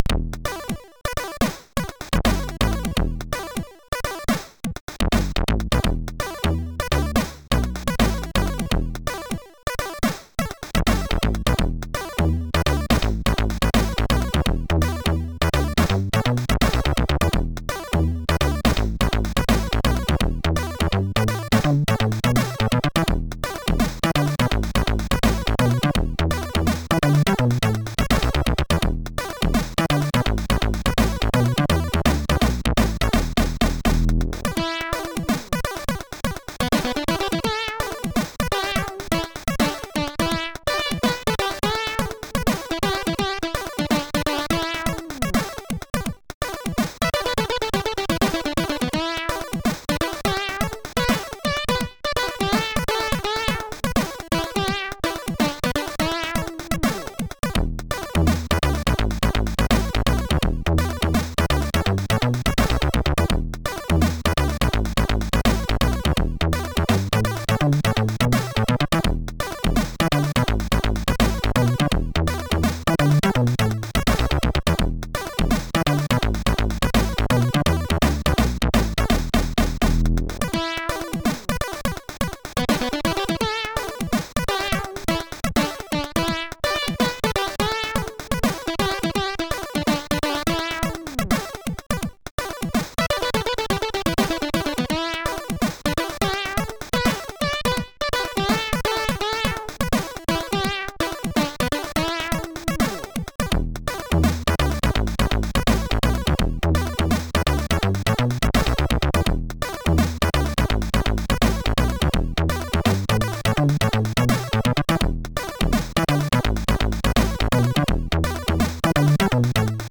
( .mp3 ) < prev next > Commodore SID Music File | 1997-01-25 | 4KB | 1 channel | 44,100 sample rate | 3 minutes